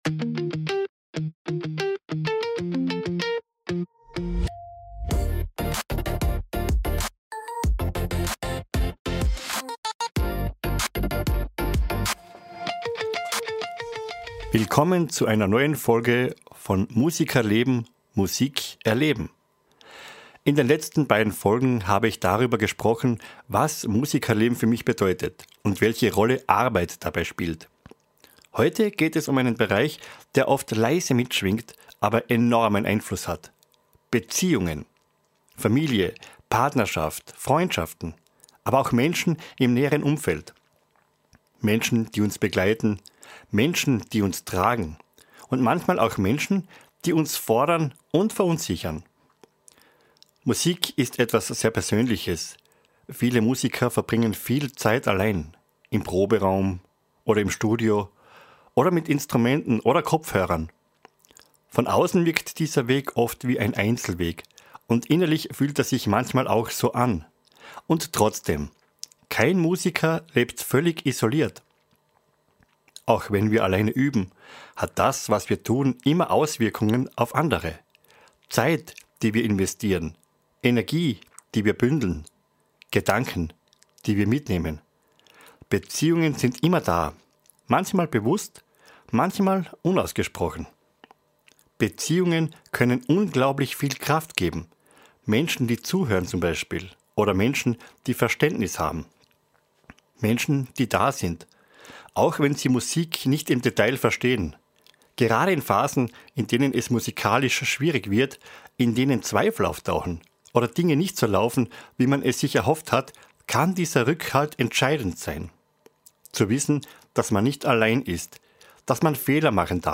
Eine ruhige, persönliche Reflexion über einen Lebensbereich, der